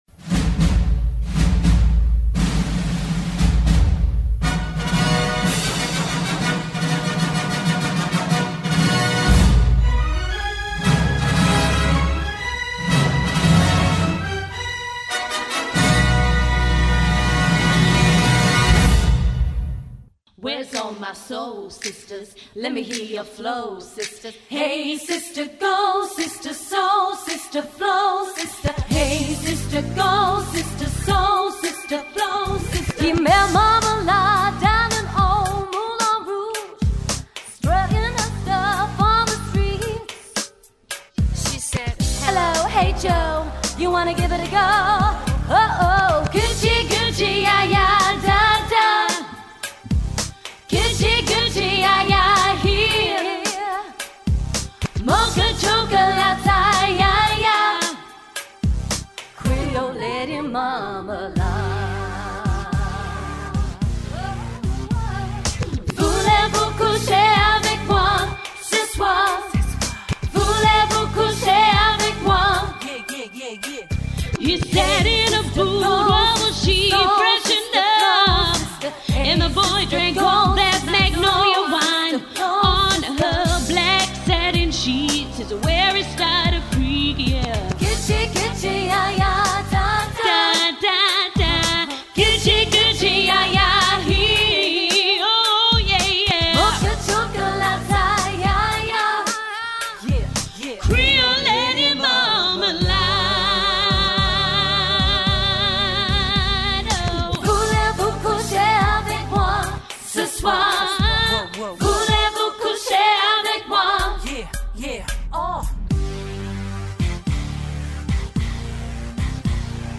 in a musical theatre style